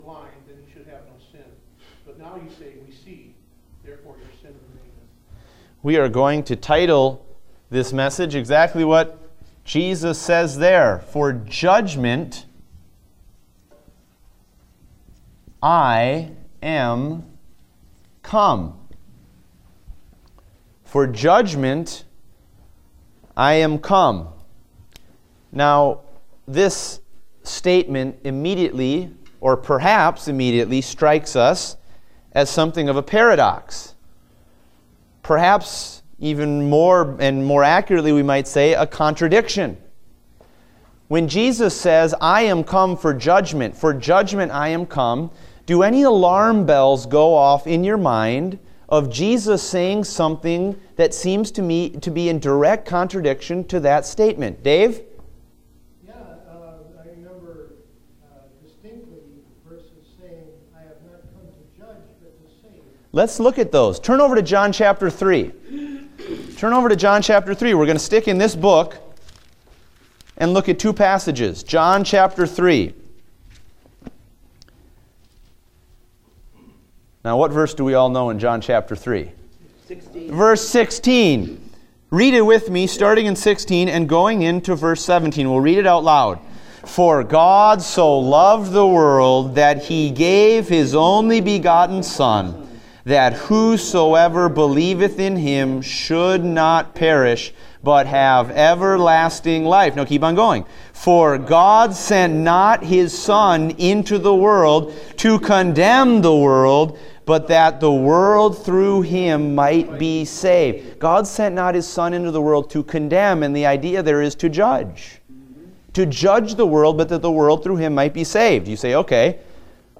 Date: October 30, 2016 (Adult Sunday School)